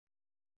♪ paṭṭikāra